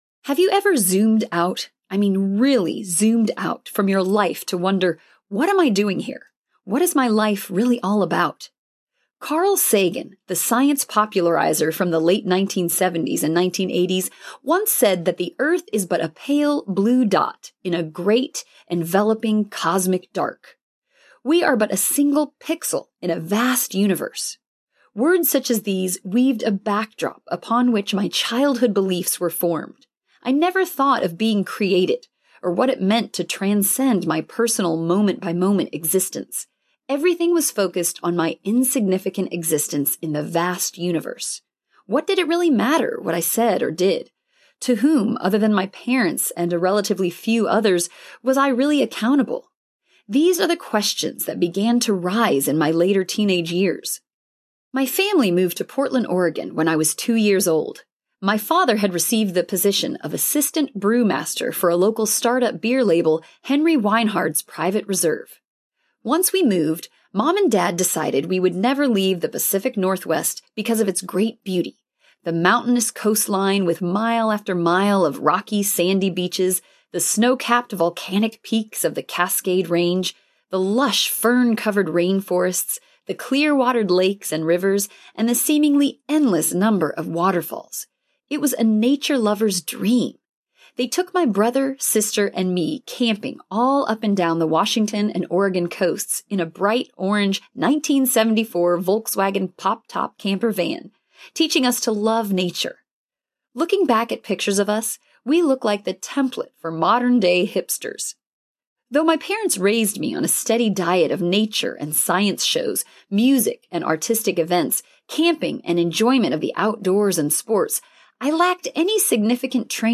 Why I Still Believe Audiobook
Narrator
5.8 Hrs. – Unabridged